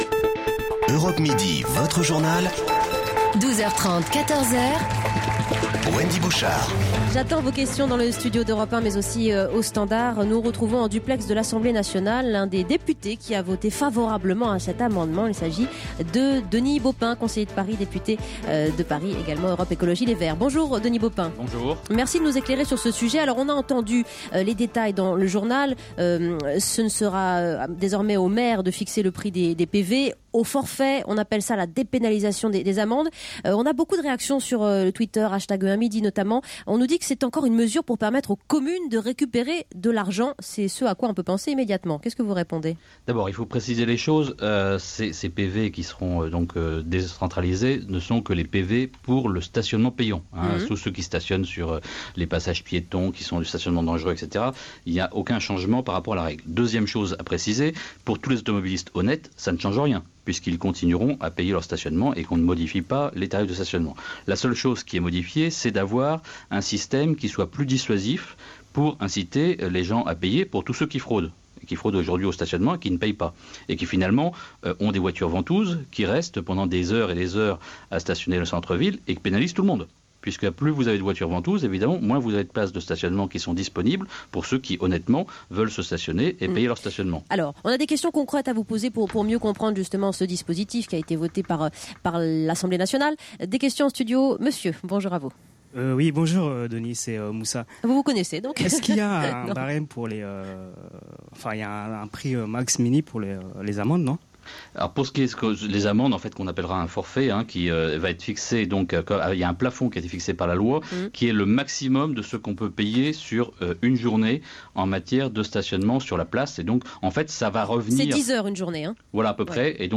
Europe 1 midi – Denis Baupin interviewé sur les PV de stationnement
Denis Baupin répond aux questions de Wendy Bouchard dans Europe midi Votre Journal -13/12/13